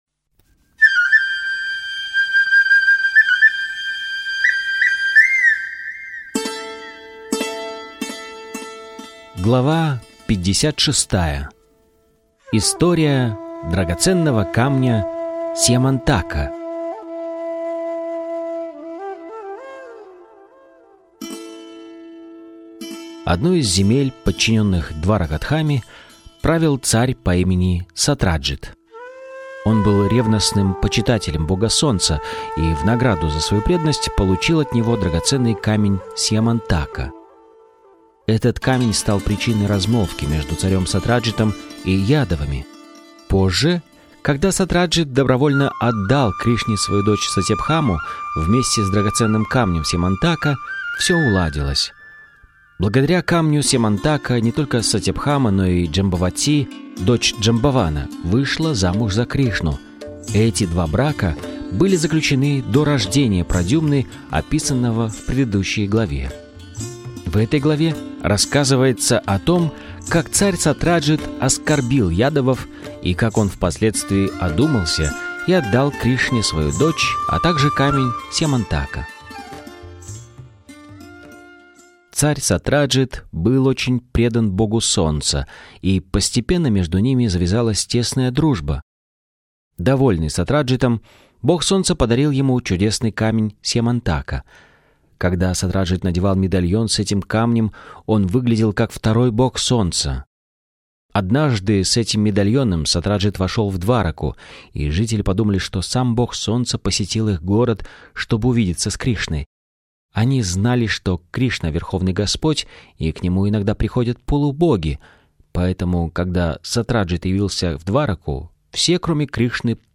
Источник вечного наслаждения автор Абхай Чаран Де Бхактиведанта Свами Прабхупада Информация о треке Автор аудиокниги : Абхай Чаран Де Бхактиведанта Свами Прабхупада Аудиокнига : Кришна.